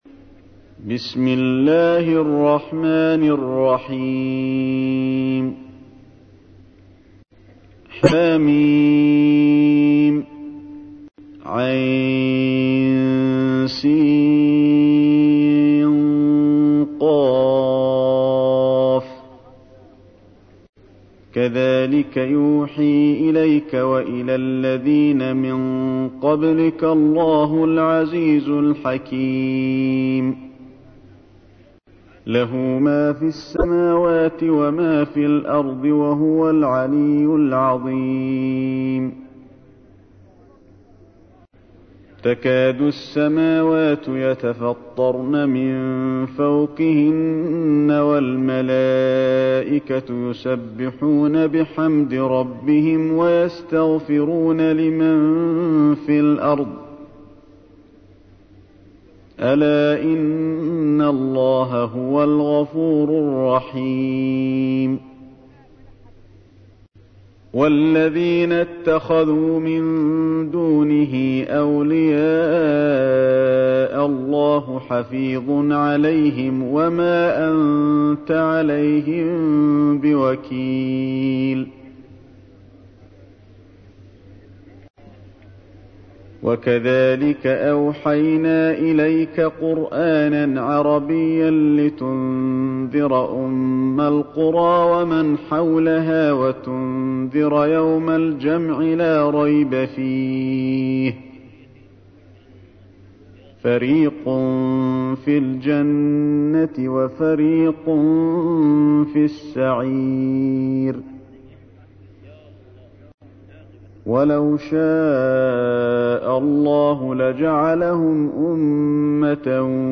تحميل : 42. سورة الشورى / القارئ علي الحذيفي / القرآن الكريم / موقع يا حسين